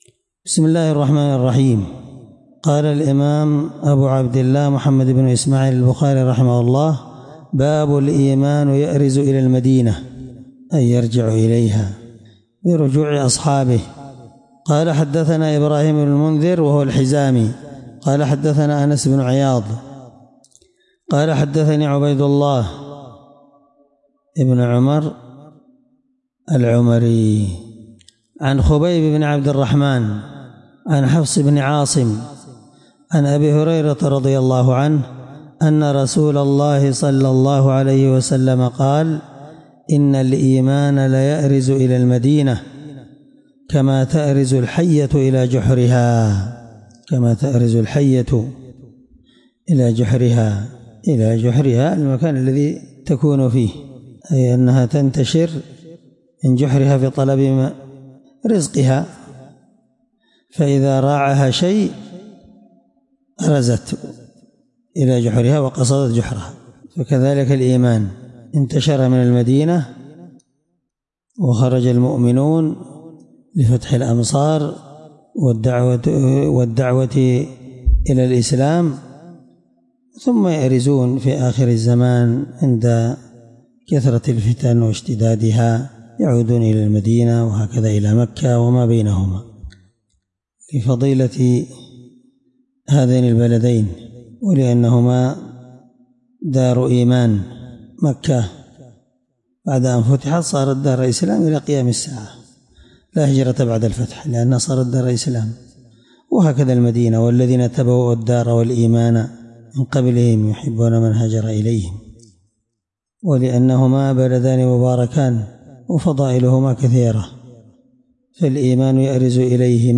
الدرس7من شرح كتاب فضائل المدينة حديث رقم(1876 )من صحيح البخاري